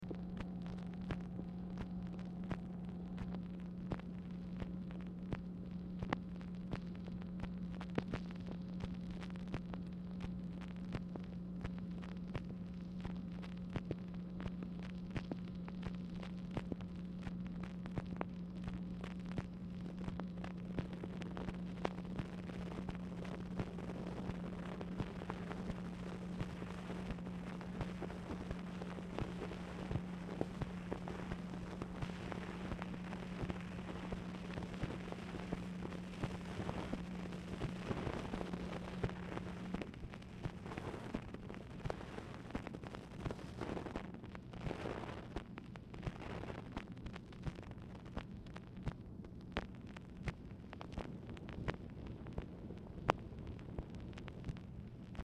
MACHINE NOISE
Format Dictation belt